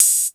Closed Hats
Hat_88.wav